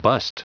Prononciation du mot bust en anglais (fichier audio)